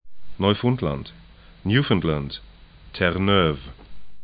nɔy'fʊntlant